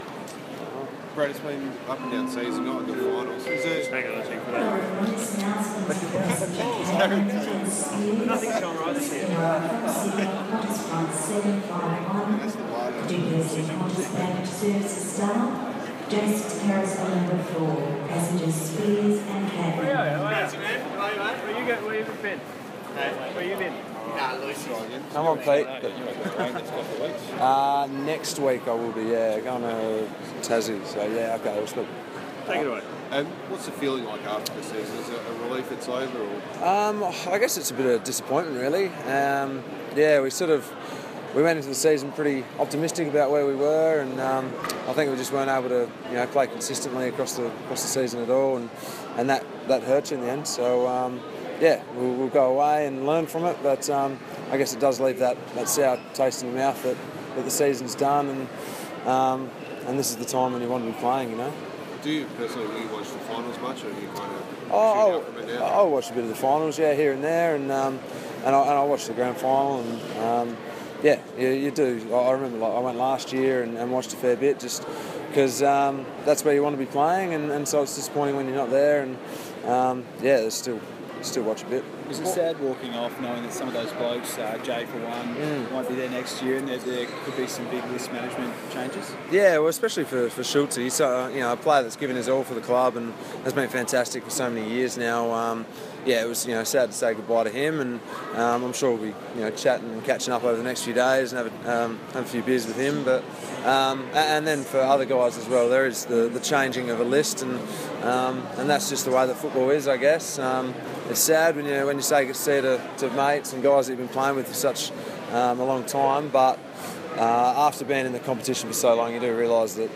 Brad Ebert press conference - Sunday, 28 August, 2016
Brad Ebert talks with media after returning from the Gold Coast.